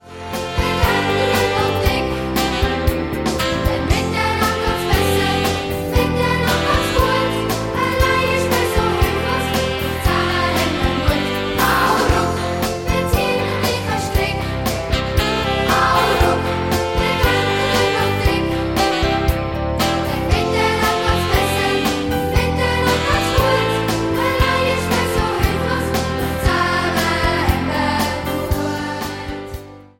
Musical-Album